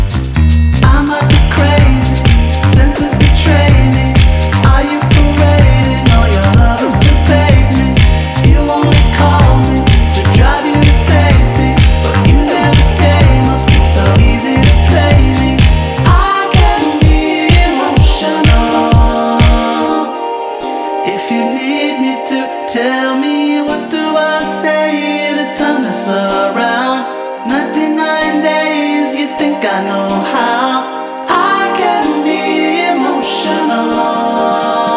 Жанр: Танцевальные / Альтернатива